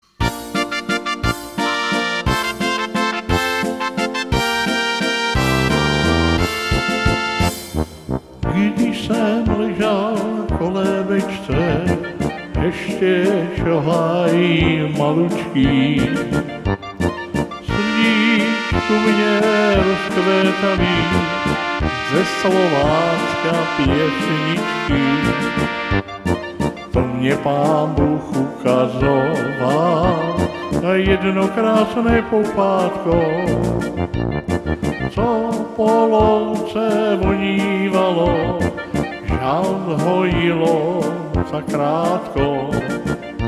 Rubrika: Národní, lidové, dechovka
Karaoke